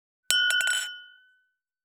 295,夜景の見えるレストラン,チーン,カラン,キン,コーン,チリリン,カチン,チャリーン,クラン,カチャン,クリン,シャリン,チキン,コチン,
コップ効果音厨房/台所/レストラン/kitchen食器
コップ